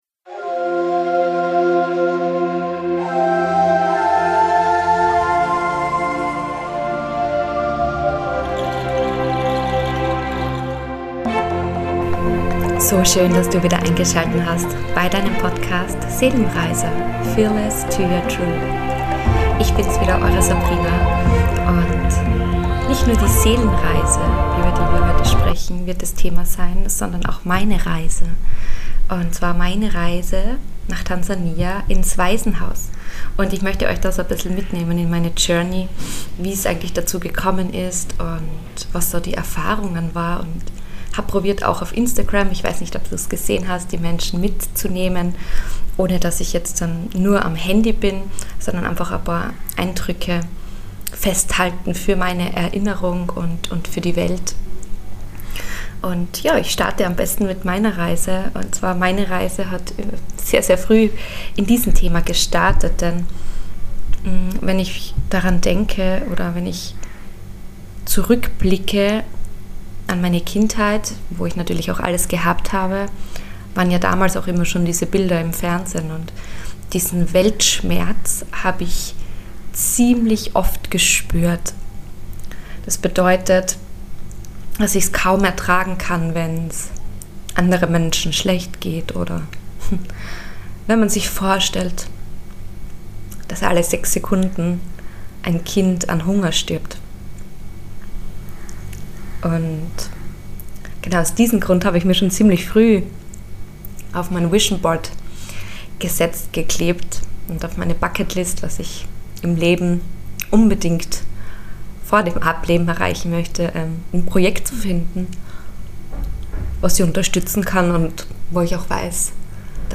Ihr werdet bestimmt an meiner Stimme merken, dass ich die eine oder andere Däne vergossen habe beim einsprechen. Ich habe mir aber fest vorgenommen nichts zu schneiden und wollte meinen Gefühlen auch den Raum geben, den sie verdient haben.